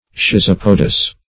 Search Result for " schizopodous" : The Collaborative International Dictionary of English v.0.48: Schizopod \Schiz"o*pod\ (?; 277), Schizopodous \Schi*zop"o*dous\, a. Of or pertaining to a schizopod, or the Schizopoda.